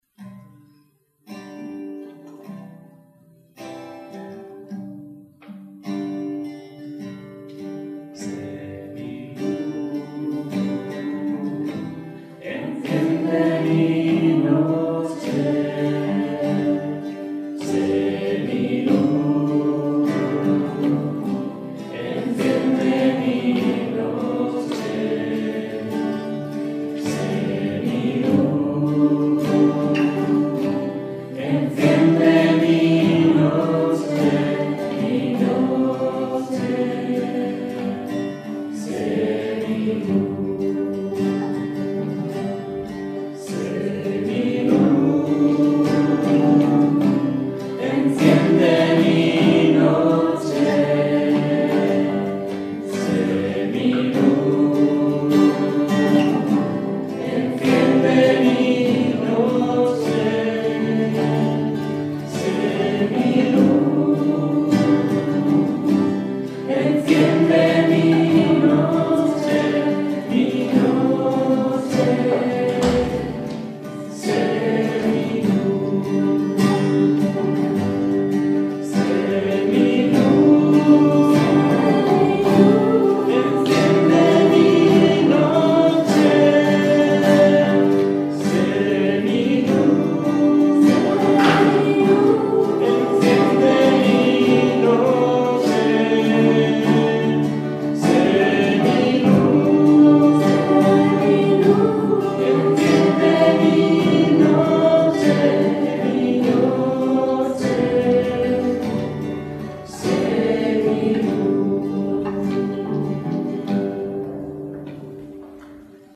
Canto: